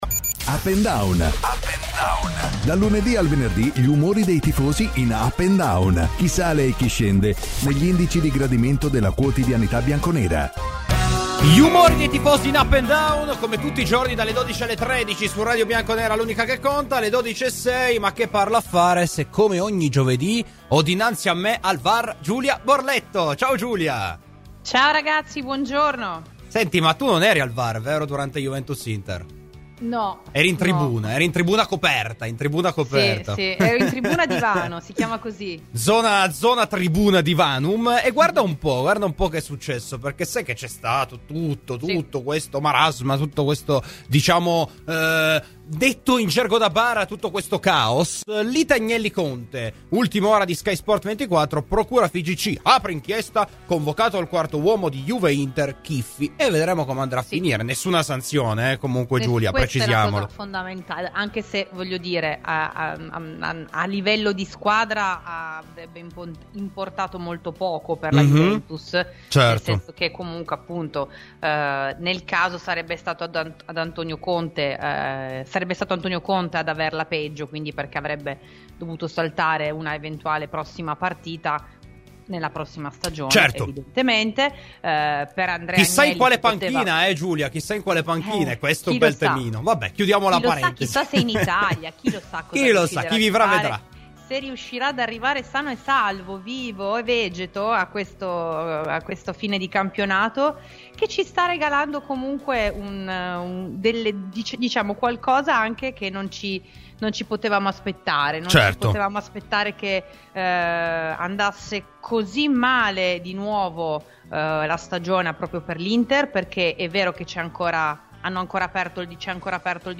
Intervistato da Radio Bianconera